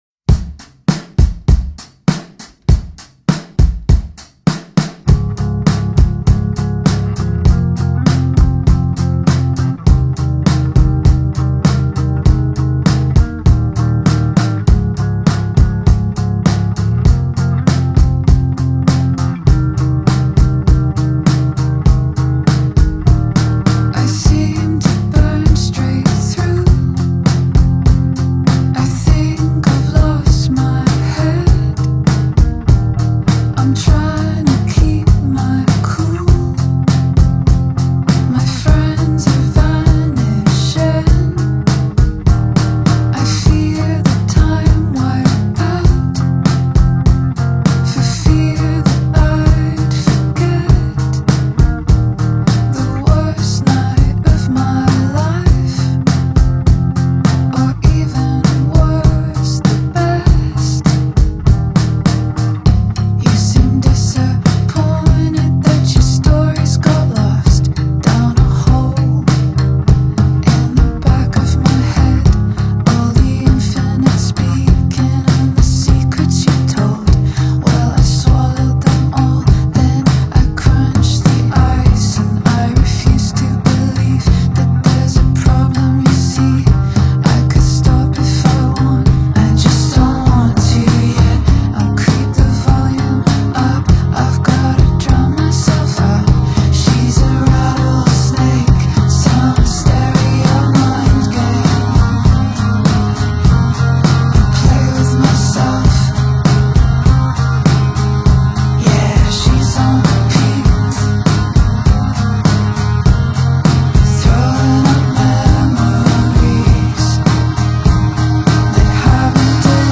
indie folk trio